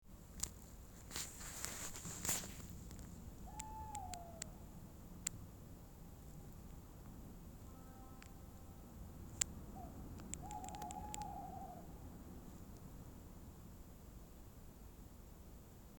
Birds -> Owls ->
Tawny Owl, Strix aluco
StatusSinging male in breeding season